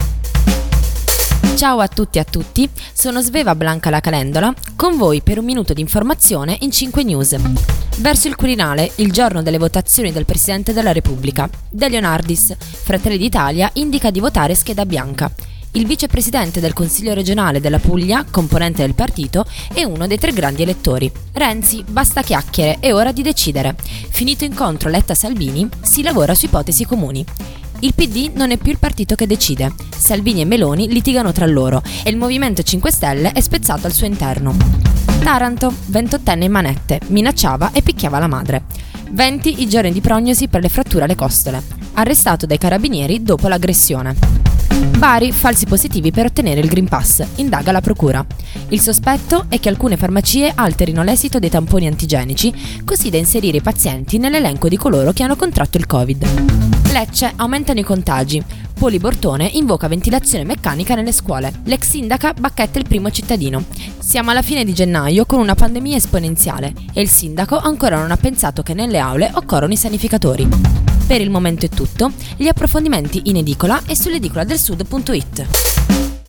Giornale radio alle ore 13.